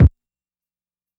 KICK IIIIIII.wav